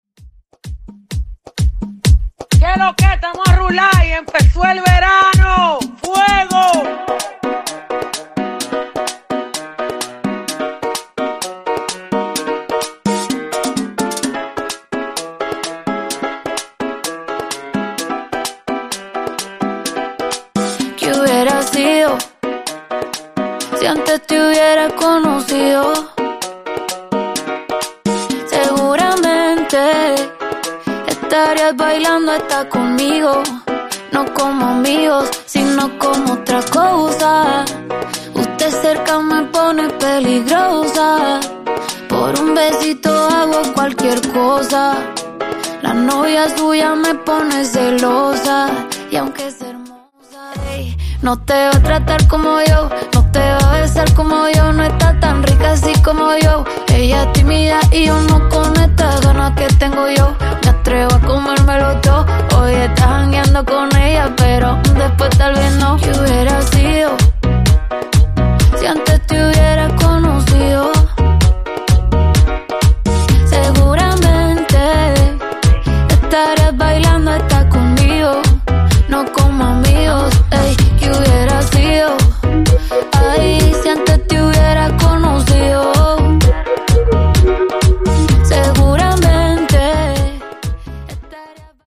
Genre: R & B
Dirty BPM: 111 Time